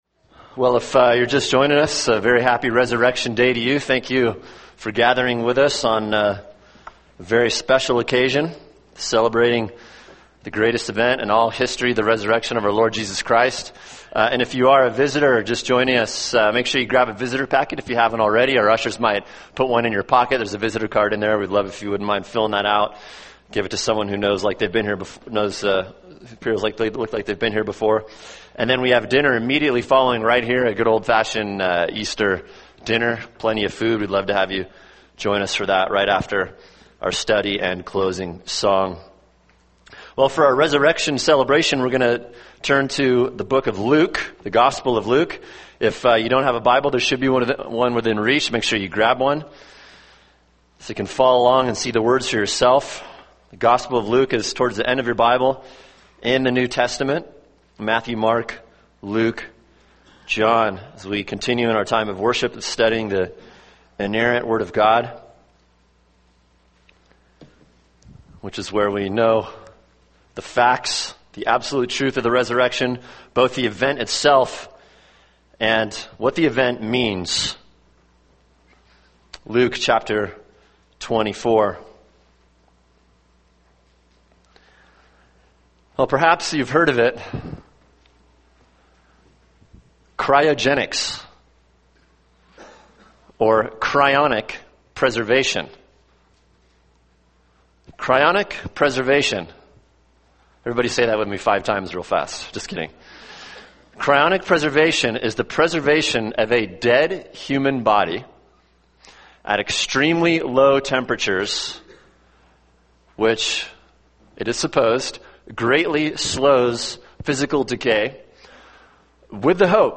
[sermon] Luke 24:13-35 – Resurrected by the Word | Cornerstone Church - Jackson Hole